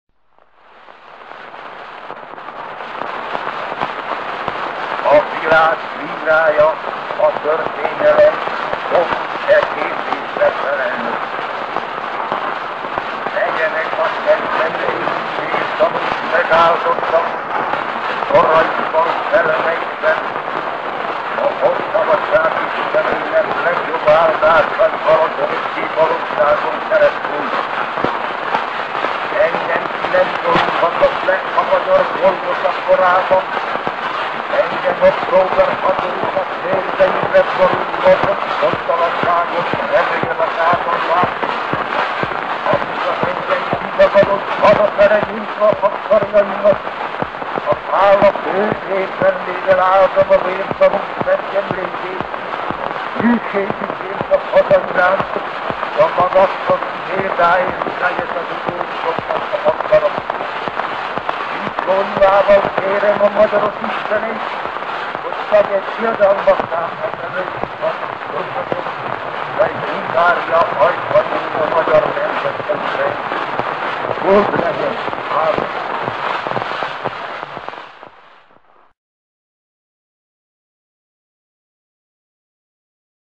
1890년 토리노를 방문한 헝가리 순례자 대표단은 코슈트의 짧은 애국 연설을 녹음했다. 이 녹음은 축음기의 초기 적용 사례 중 하나이며,[100][101] 현존하는 가장 초기의 헝가리어 녹음 연설이다.[102]
코슈트 러요시의 목소리(1890년 9월 20일, 이탈리아 토리노)